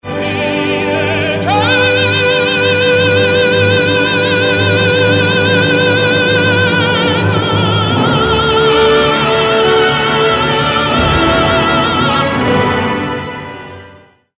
opera